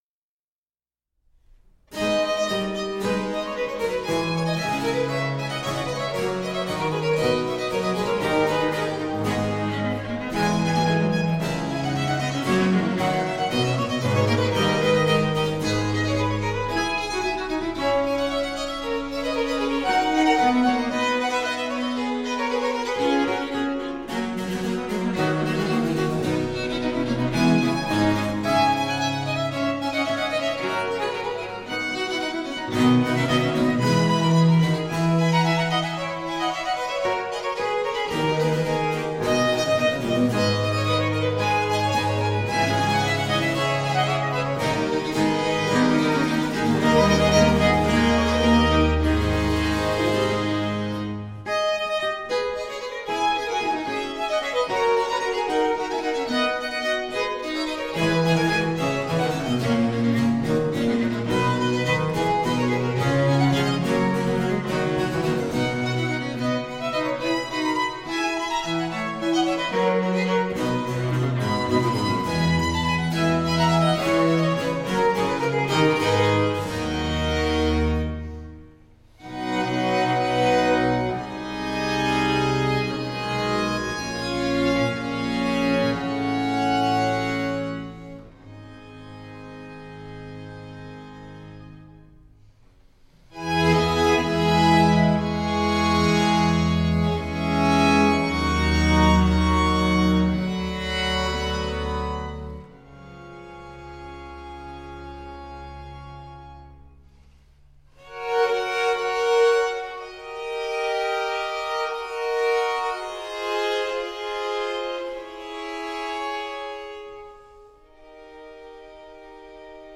• Genres: Early Music
Baroque string ensemble